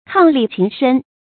伉俪情深 kāng lì qíng shēn 成语解释 伉俪：夫妻，配偶。